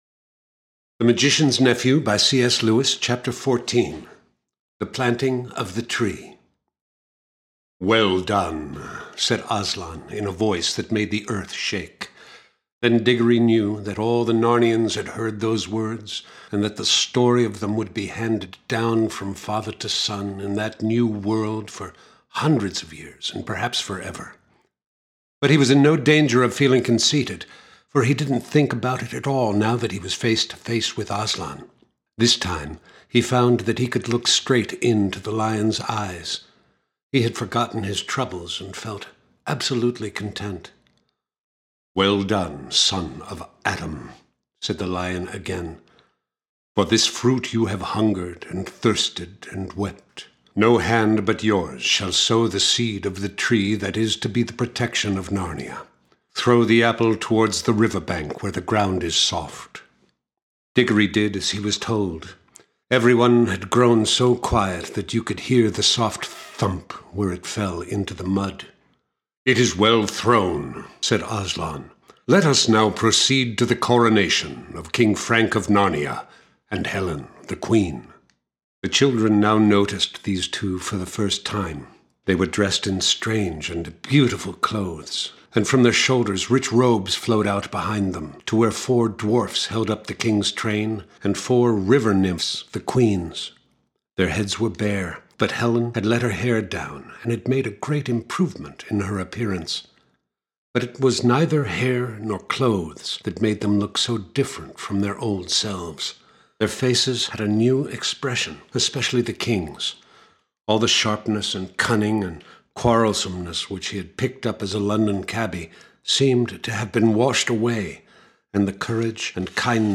Locked in during the COVID crisis